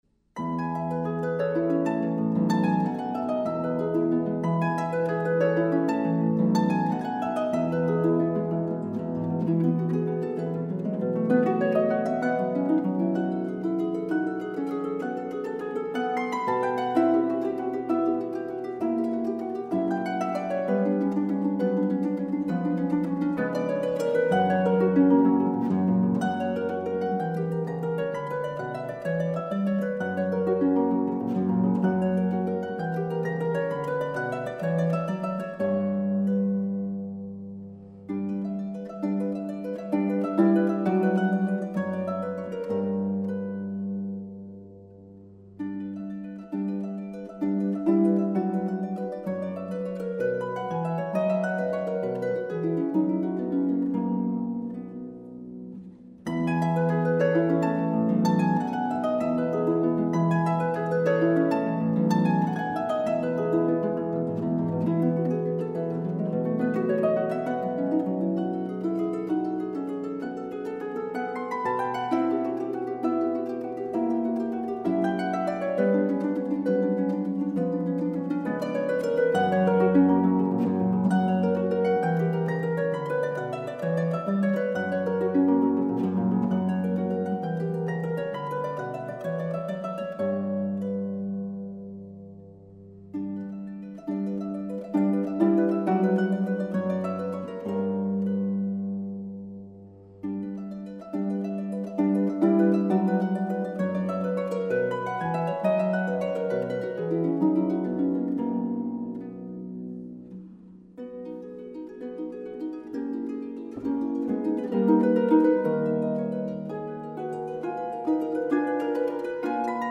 Harfe
D. Scarlatti: Sonate E-Dur K 531